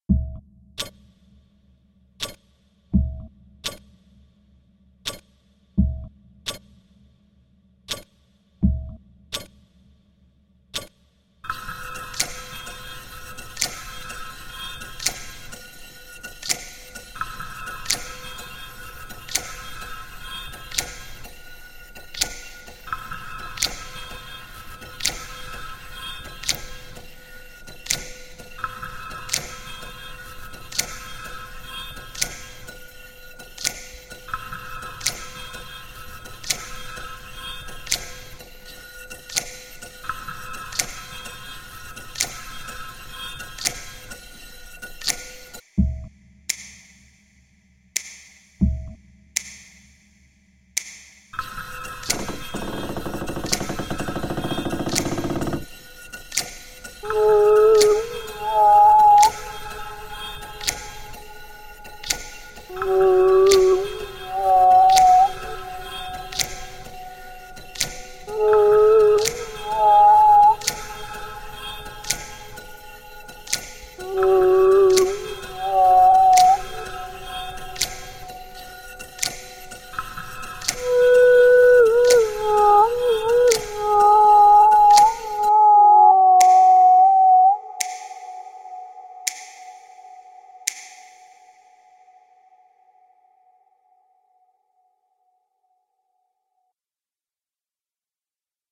PAYSAGE MUSICAL
En utilisant seulement des objets trouvés transformés,